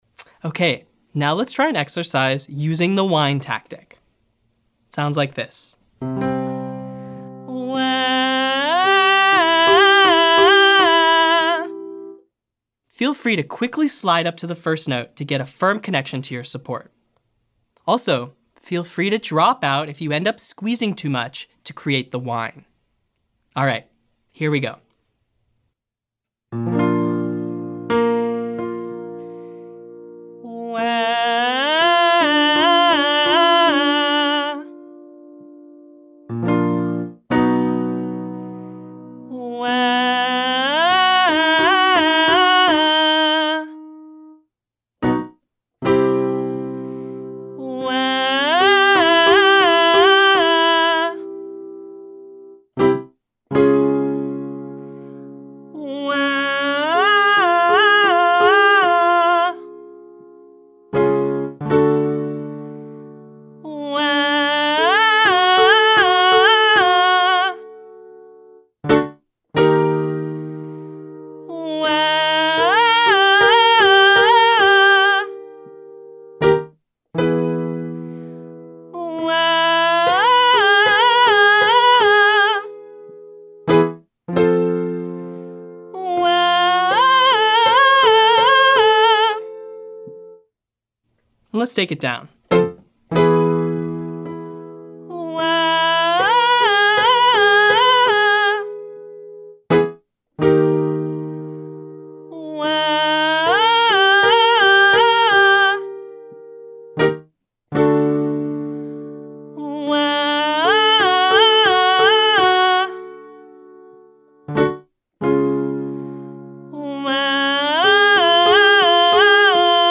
Try imitating this whine sound with me on a Wangh for a bit.
I’m using the iconic millennial whoop for this one, back and forth from 5-3.